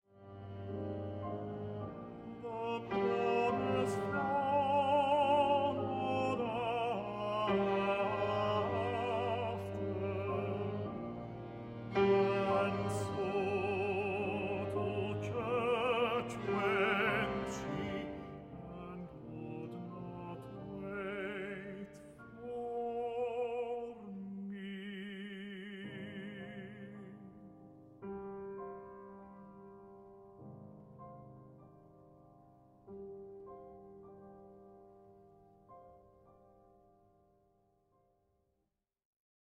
Orchestral
for tenor, piano and string quartet